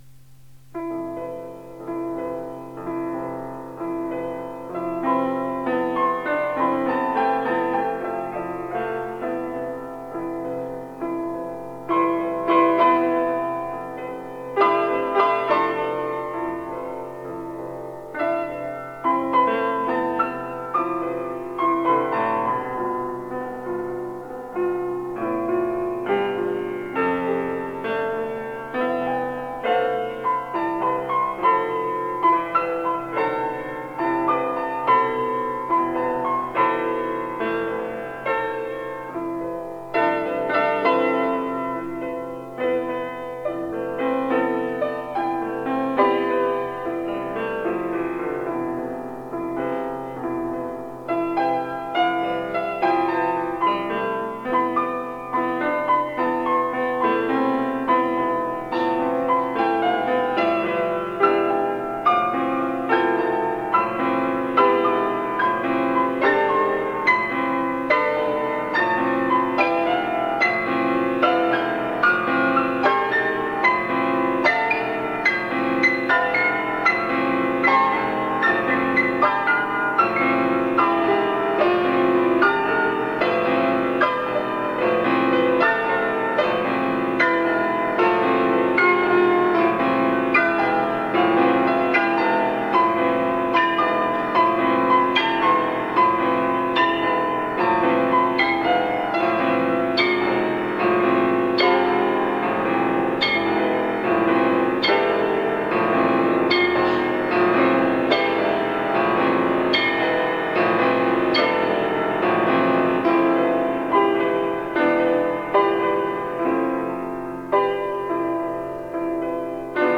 Piano Four-Hands
The brief melody was fragmented, expanded and set to a rocking pandiatonic accompaniment. I thought it would be fun to have the secondo part ascend chords from the bottom to the top of the keyboard, which resulted in plenty of four-hand gymnastics.
four-hand piano
Archival Recordings